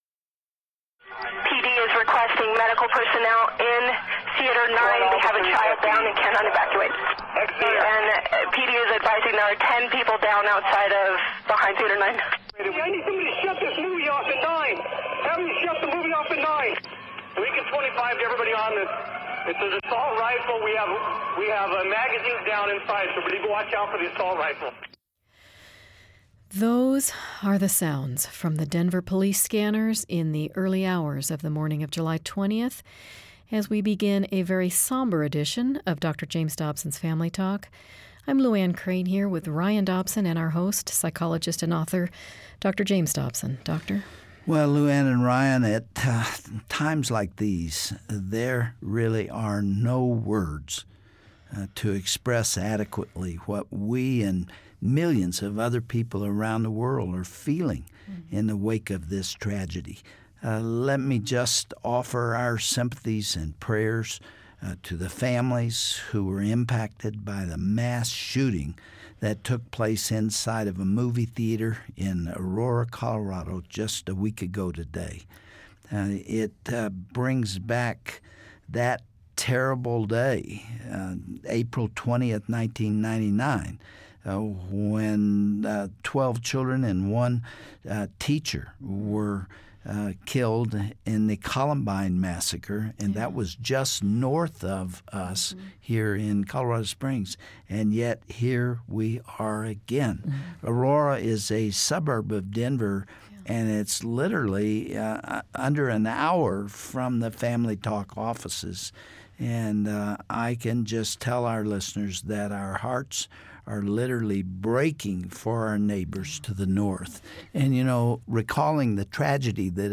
By now you've probably heard of the tragic shooting in Aurora, Colorado resulting in the loss of 12 precious lives. We all grieved at the news of this tragedy, now tune in, when host, Dr. James Dobson will sit down with some Denver pastors whose congregations have been affected by this horrific event.